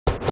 pbs - scratch 3.0 [ Perc ].wav